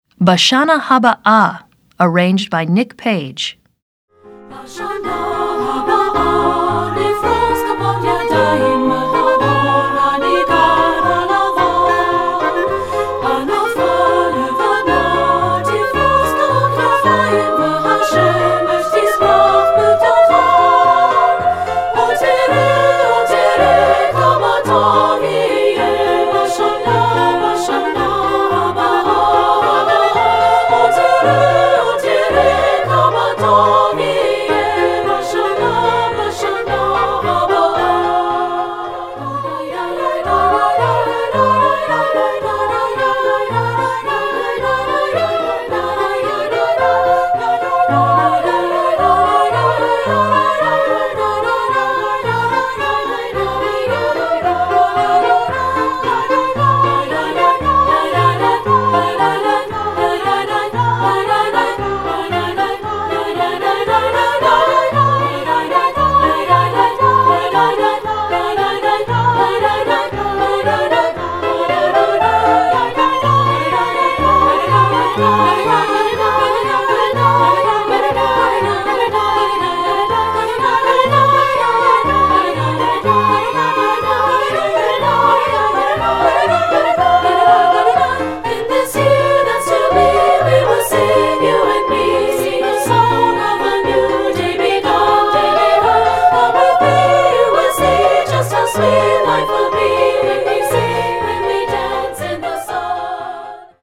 Voicing: 3-Part Treble